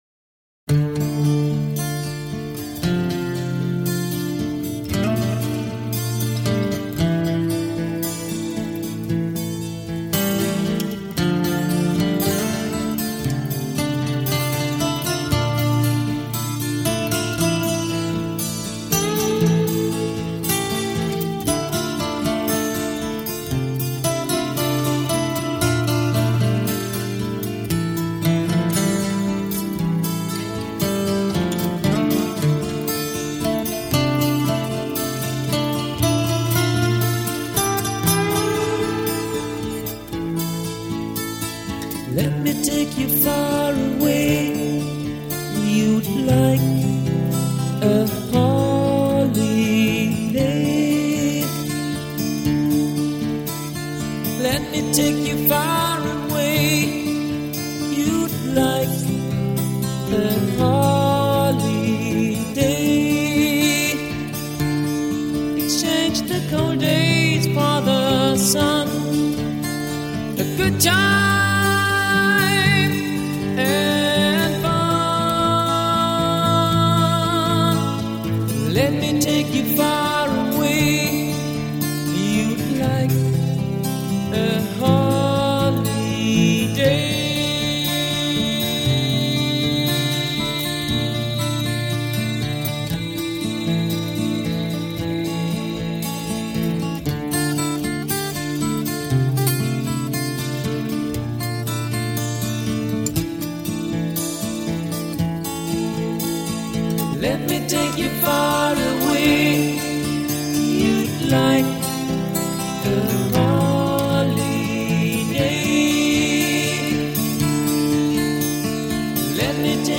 Жанр: Hard 'n' Heavy